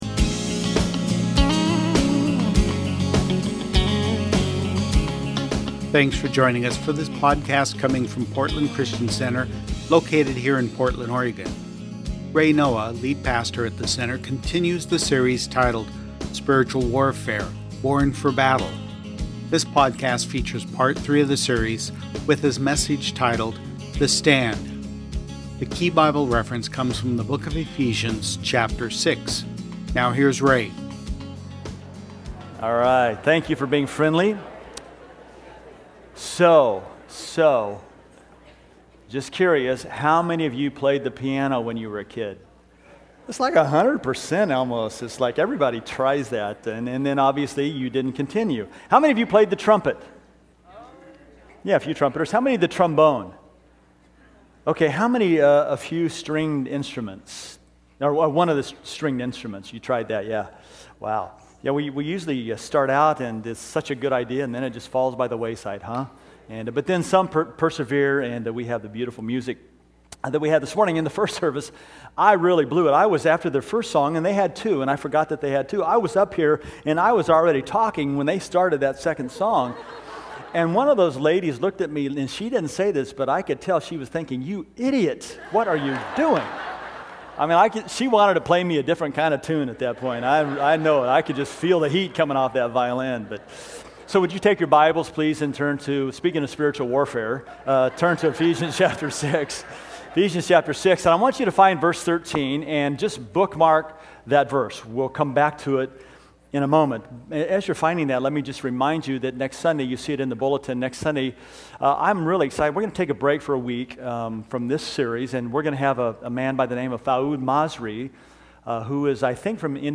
Sunday Messages from Portland Christian Center Born For Battle – Part 3: The Stand Jul 21 2013 | 00:41:02 1x 00:00 / 00:41:02 Subscribe Share Spotify RSS Feed Share Link Embed